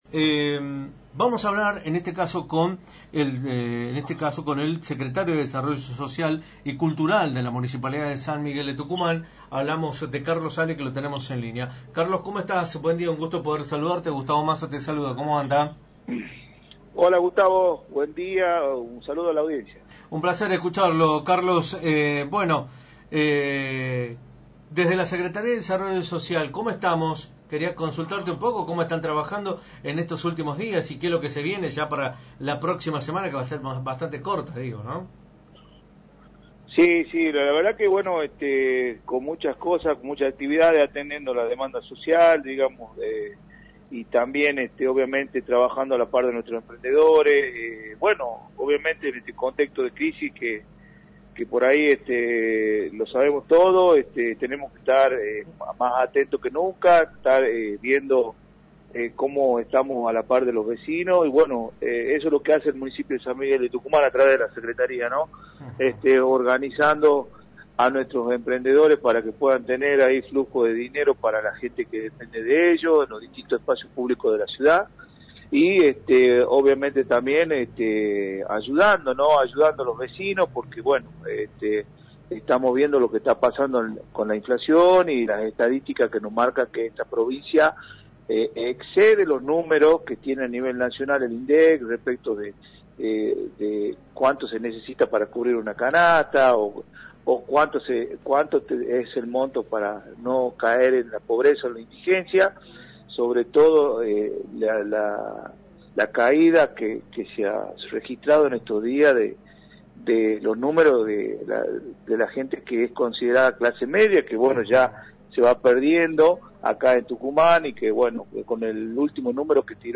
Carlos Ale, Secretario de Desarrollo Social y Cultural de la Municipalidad de San Miguel de Tucumán y candidato a Concejal, informó en Radio del Plata Tucumán, por la 93.9, cuales serán las actividades que tiene previsto el Municipio de la capital en relación al próximo fin de semana largo.